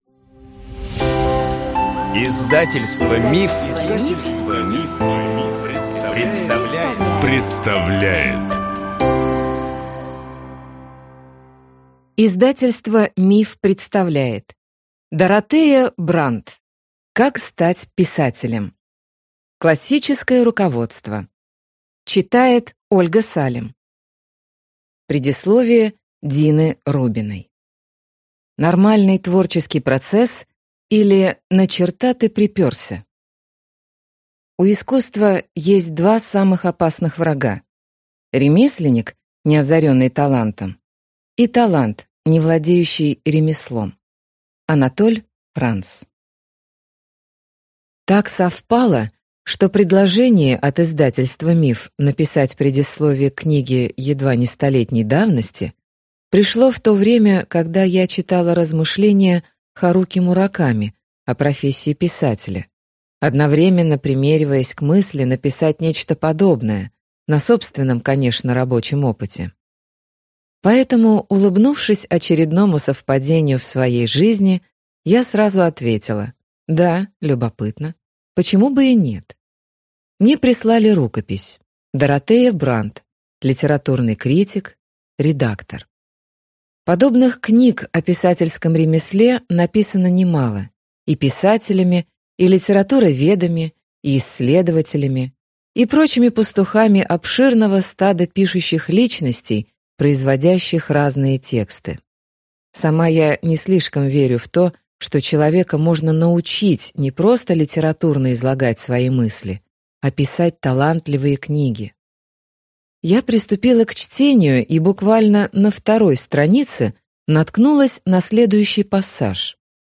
Аудиокнига Как стать писателем | Библиотека аудиокниг